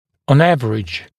[ɔn ‘ævərɪʤ][он ‘эвэридж]в среднем